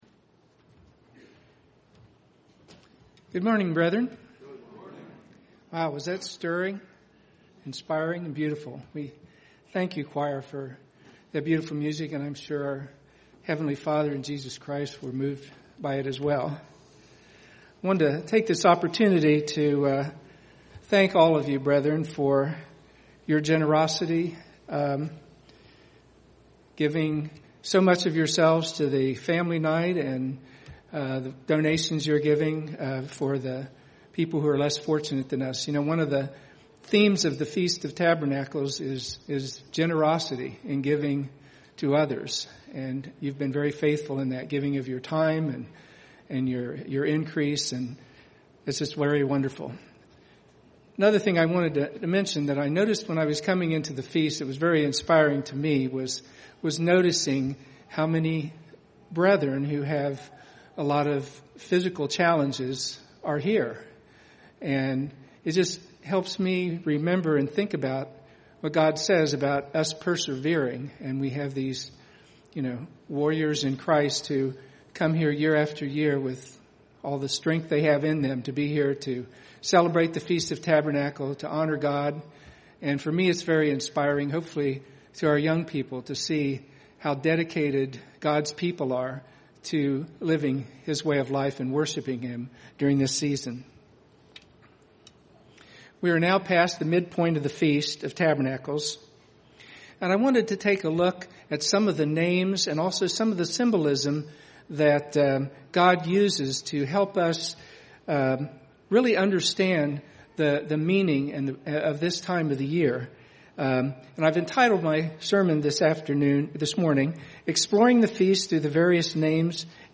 This sermon takes a look at the various symbolism and names associated with the Feast of Tabernacles to deepen our understanding of what the Feast means to all mankind.
This sermon was given at the Cincinnati, Ohio 2015 Feast site.